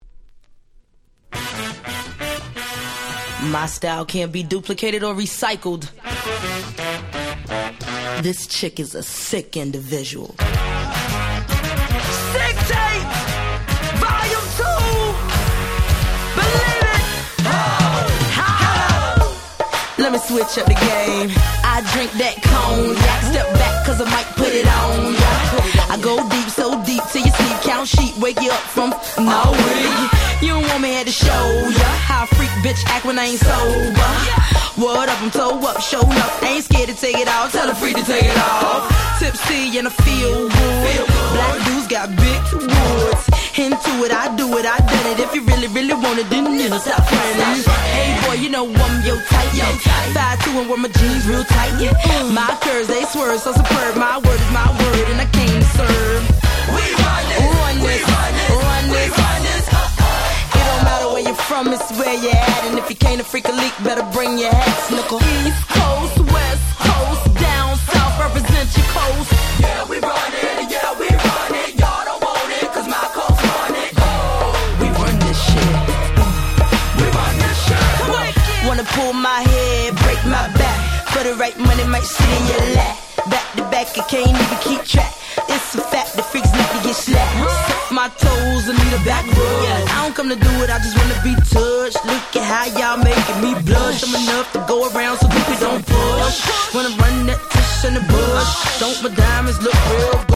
05' Smash Hit Hip Hop !!
ネタがネタだけにダンサー受け抜群のフロアキラーです！！
Old School オールドスクール